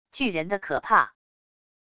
巨人的可怕wav音频